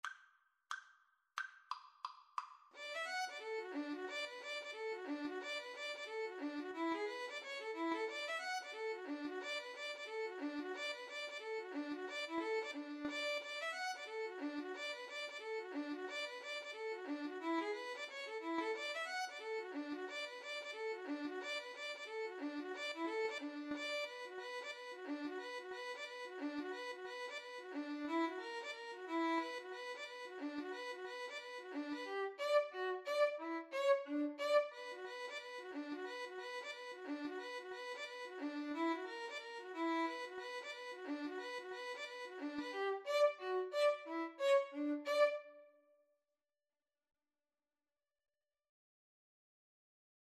Free Sheet music for Violin-Cello Duet
D major (Sounding Pitch) (View more D major Music for Violin-Cello Duet )
=90 Fast two in a bar
Traditional (View more Traditional Violin-Cello Duet Music)